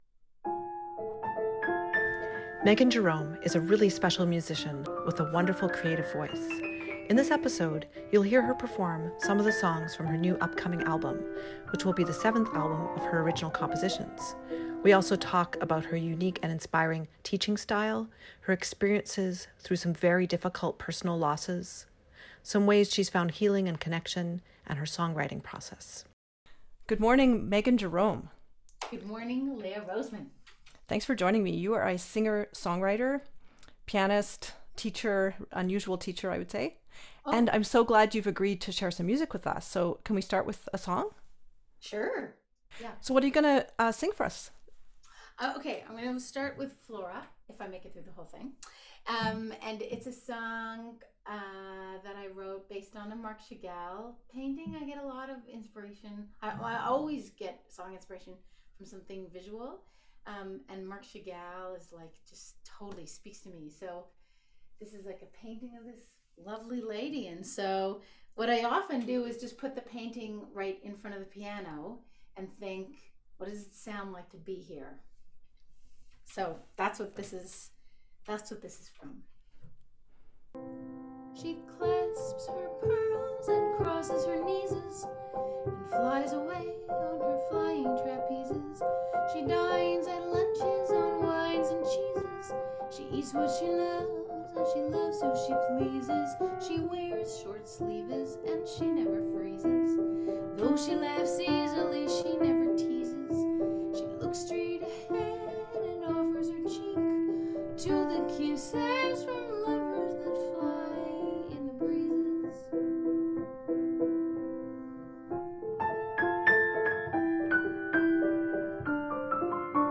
pianist, singer, song-writer, educator